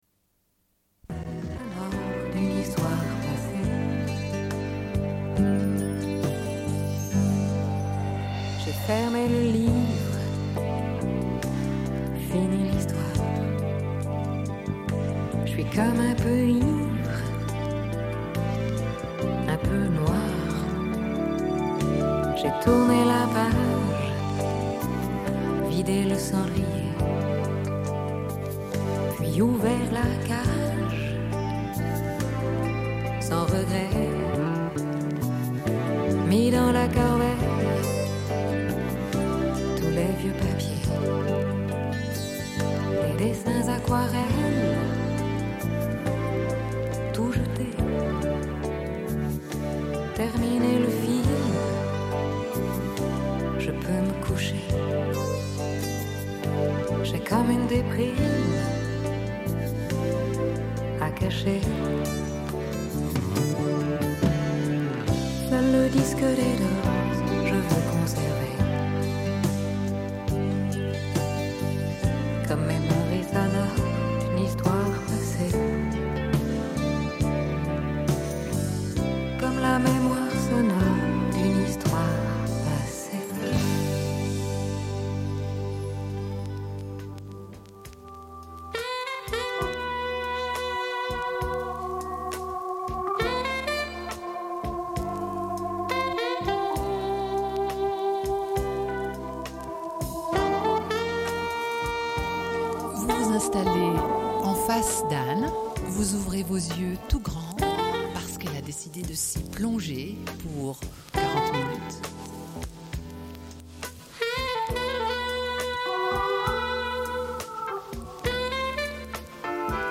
Une cassette audio, face B00:47:11